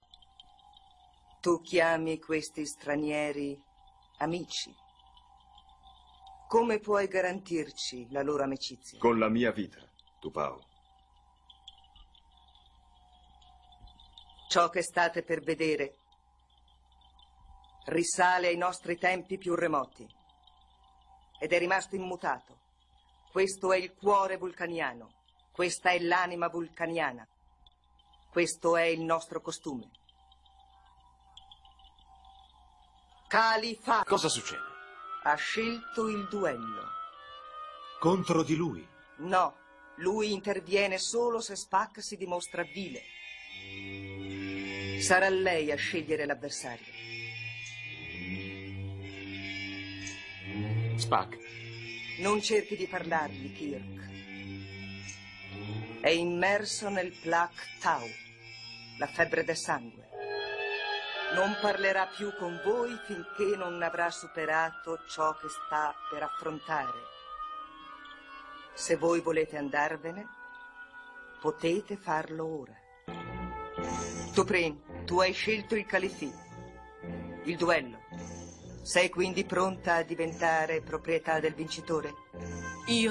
nel telefilm "Star Trek", in cui doppia Jane Wyatt.